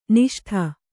♪ niṣṭha